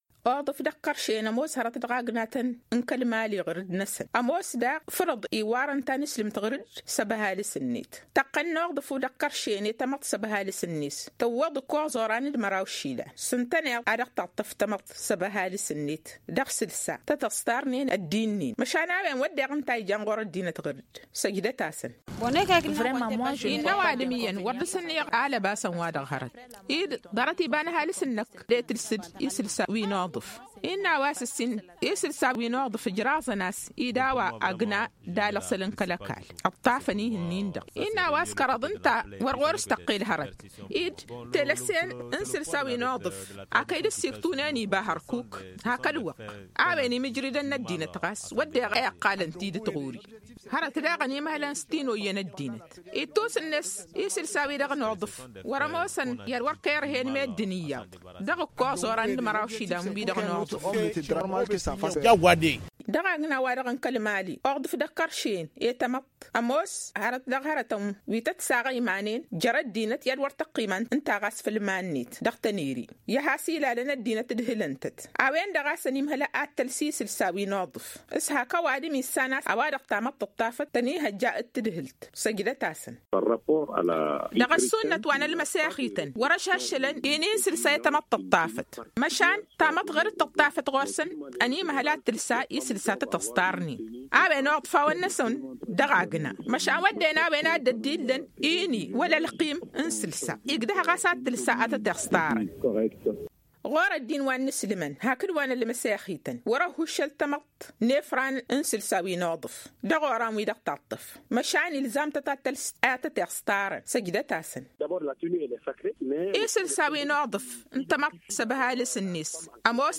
Magazine en peulh: Télécharger